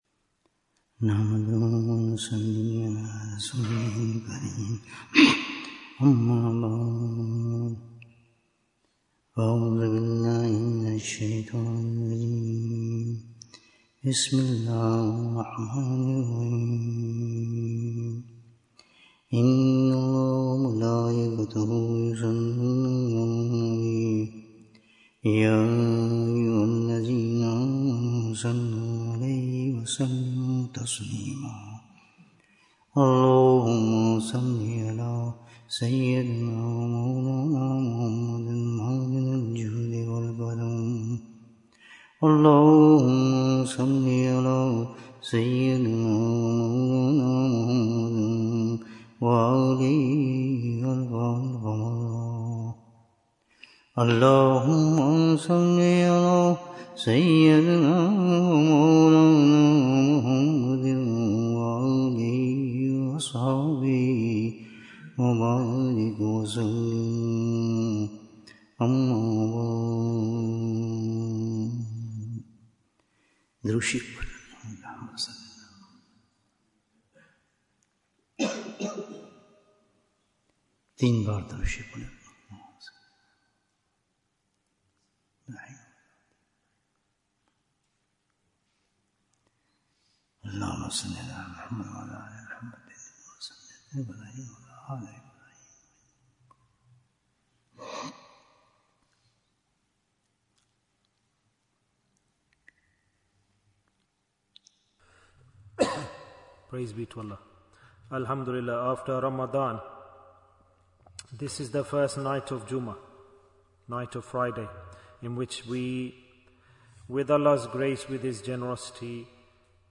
What is the Special Message of Ramadhan? Bayan, 52 minutes3rd April, 2025